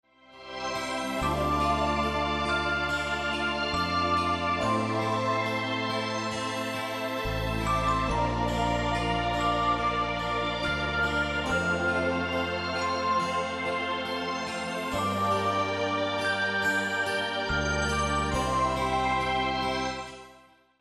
Wszystkie utwory na płycie są ze sobą połączone.